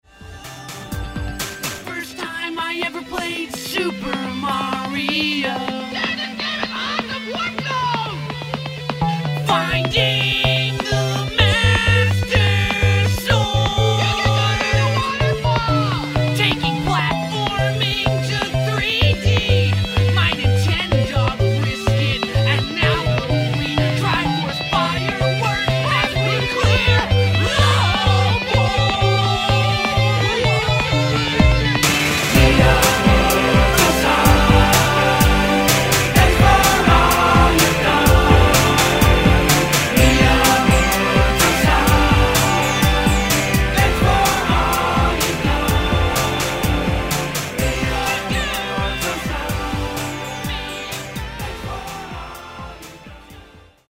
rock guitar
keytar